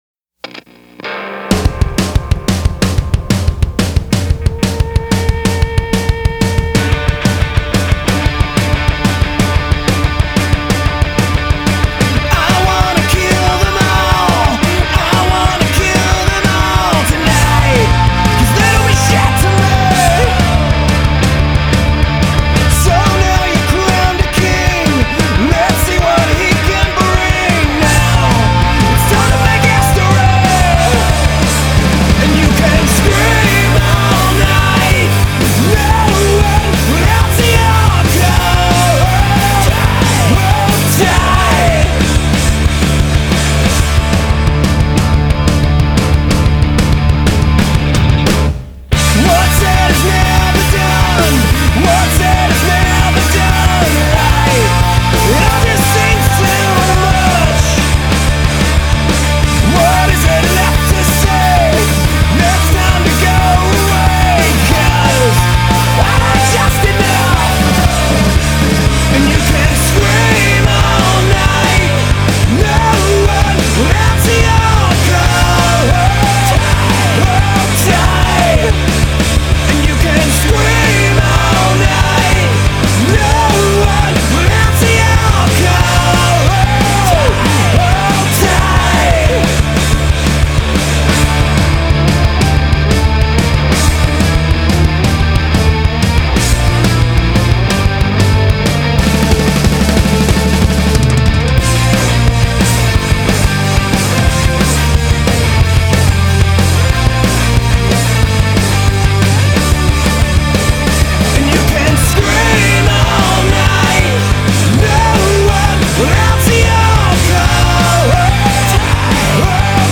FILE UNDER: Indie Rock